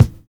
NY 7 BD.wav